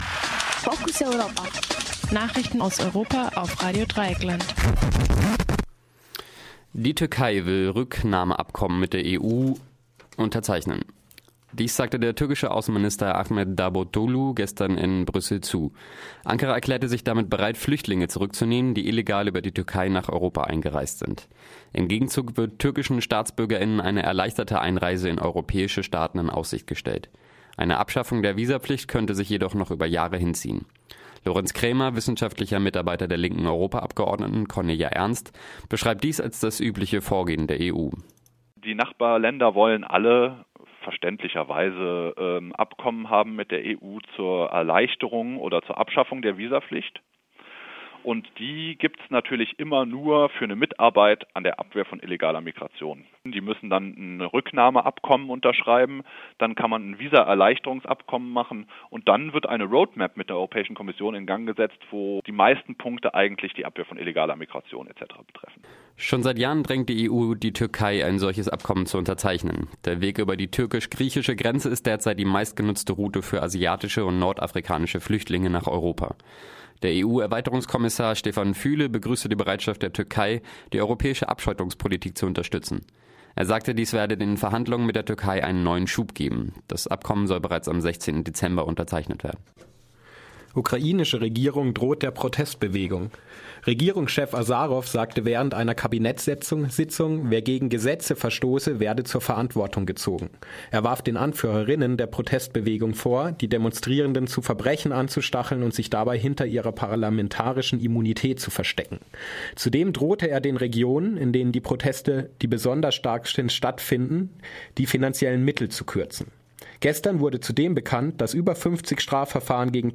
Focus Europa Nachrichten vom Donnerstag, den 5. Dezember -12.30 Uhr